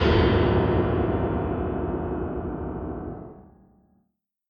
ショックな感情やシーンをイメージしたピアノ（不協和音）の効果音です。
・BPM：120
・メインの楽器：ピアノ ・テーマ：ガーン、ショック、驚愕、ホラー